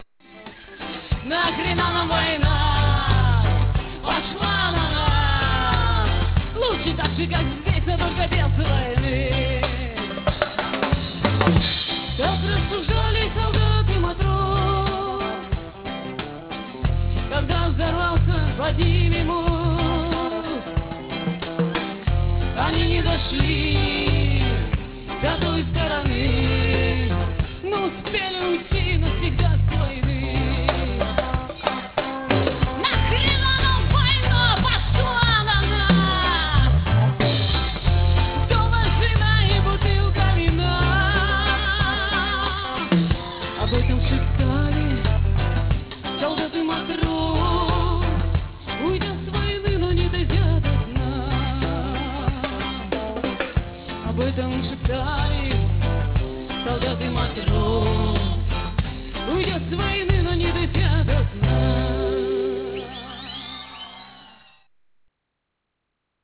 Концерт на Шаболовке (1995)
написанные в стиле регги